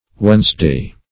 Wednesday \Wednes"day\ (?; 48), n. [OE. wednesdai, wodnesdei,